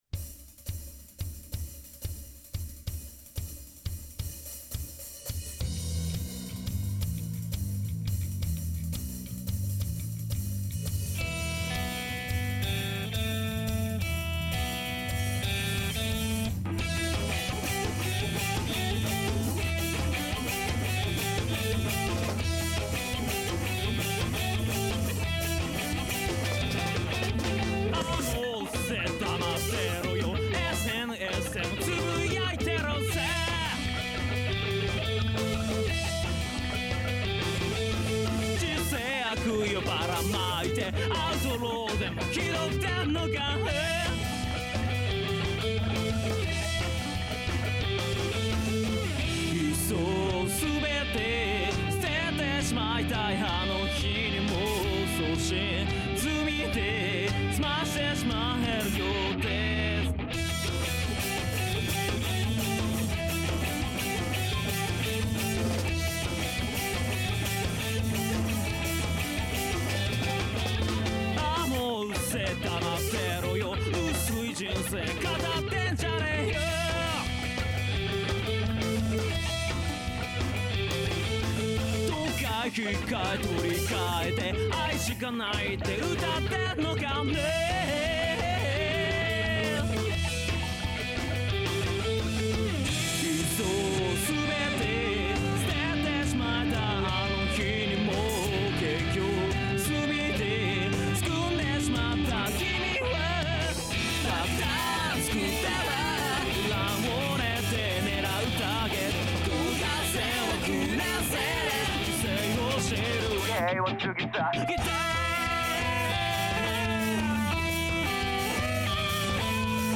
Vo＆B
Vo＆G